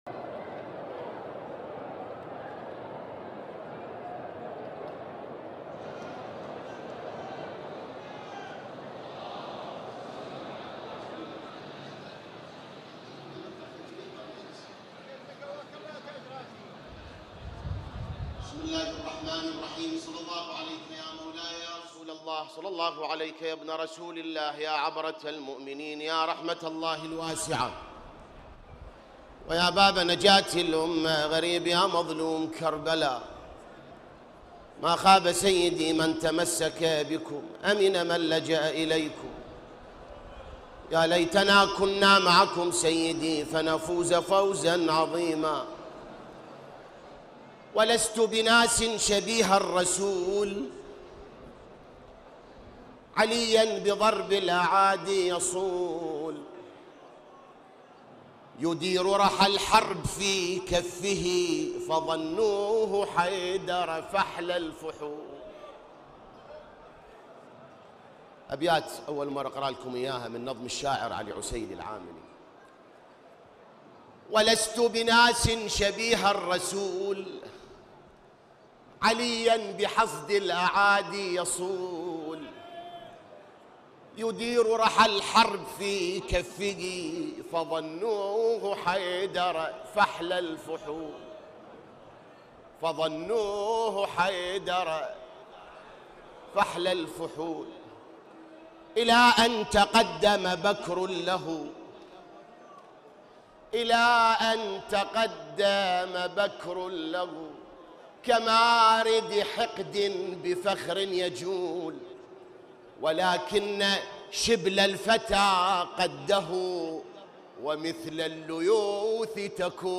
🔴 من الصحن الحسيني || يوم ٩محرم ١٤٤٧ هـ